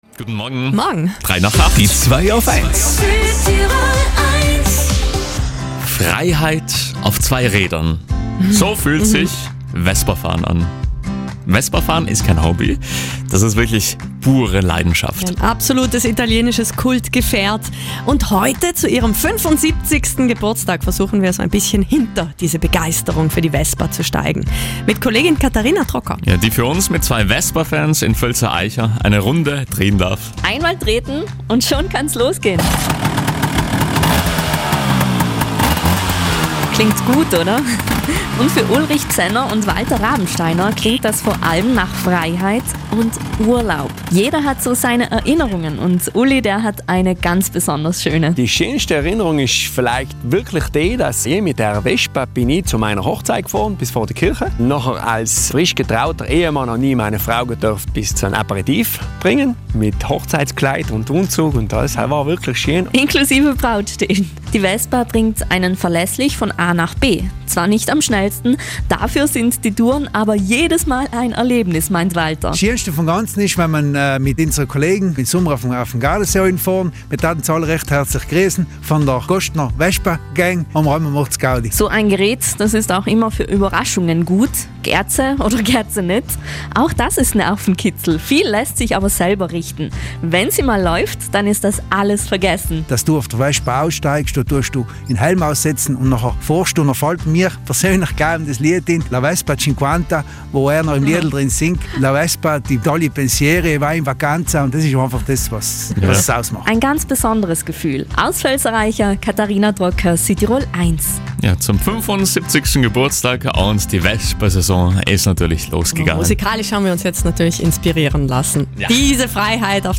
hat sich mit zwei Vespafans in Völser Aicha getroffen und eine Runde auf ihren Vespas gemacht.